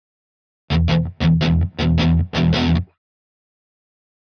Guitar Slow Heavy Metal Rhythm - Short